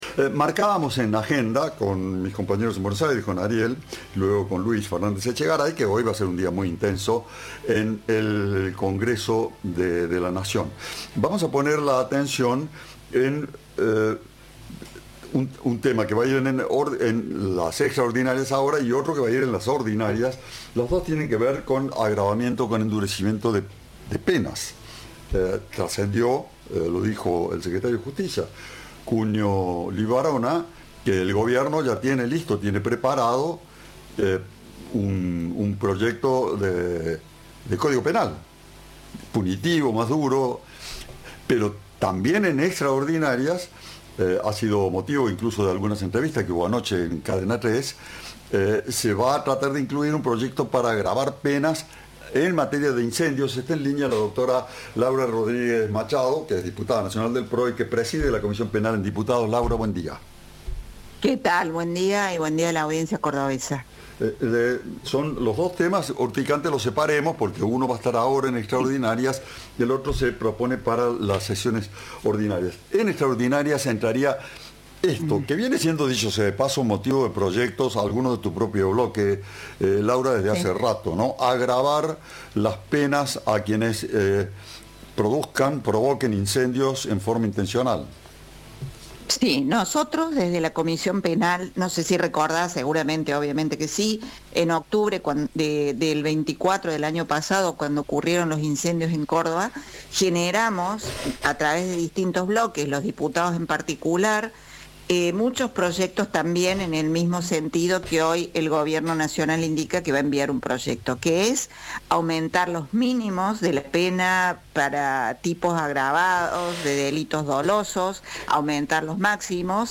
La diputada Laura Rodríguez Machado dijo a Cadena 3 que si bien la iniciativa ingresará en extraordinarias se terminará de tratar en ordinarias.